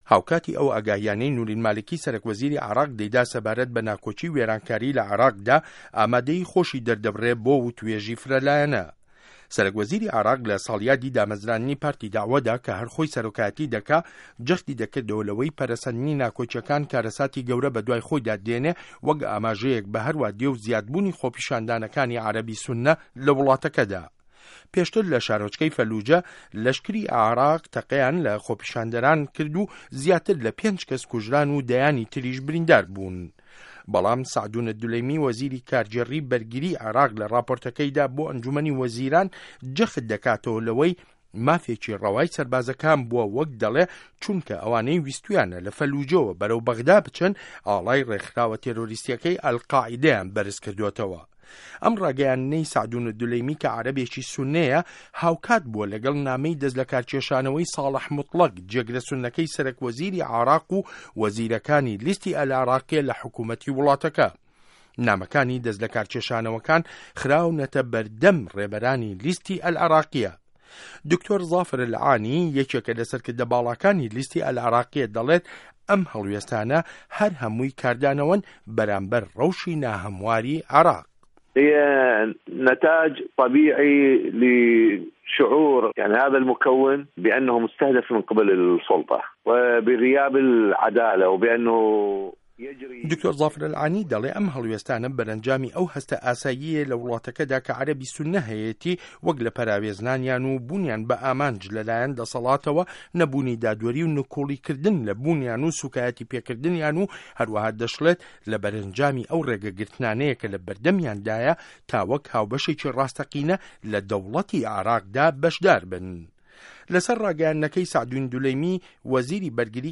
ڕاپۆرت له‌سه‌ر لێدوانه‌کانی دکتۆر زافر ئه‌لعانی